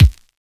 DJP_KICK_ (38).wav